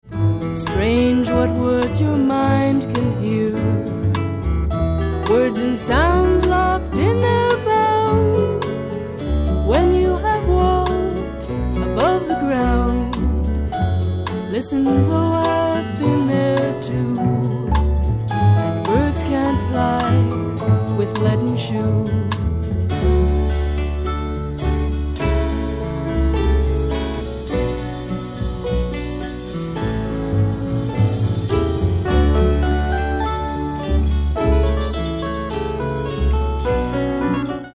ACID FOLK/RARE GROOVE